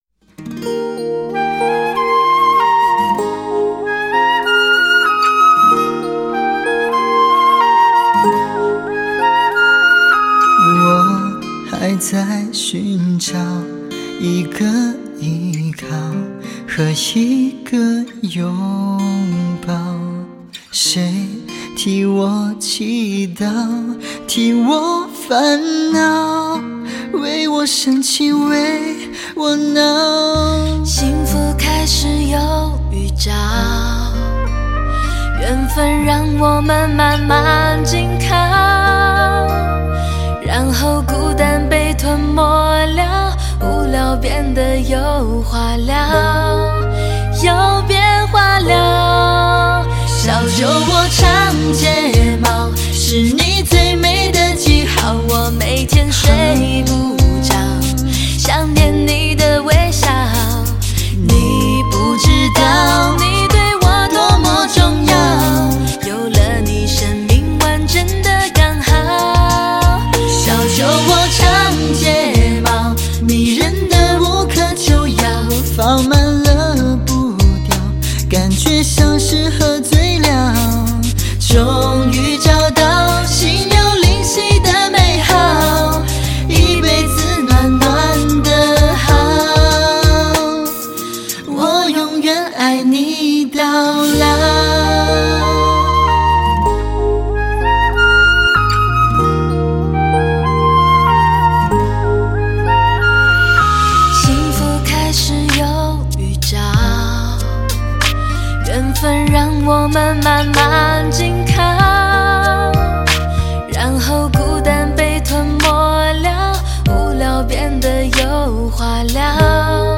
(对唱)